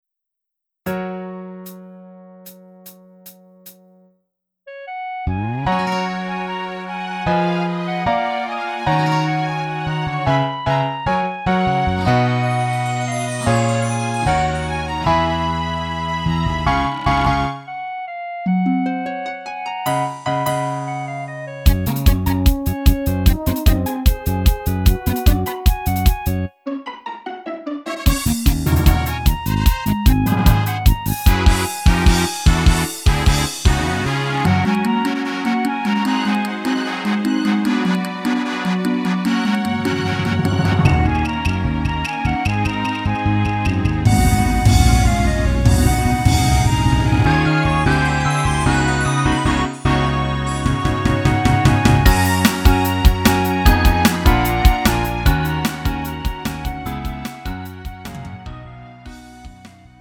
음정 원키 2:33
장르 가요 구분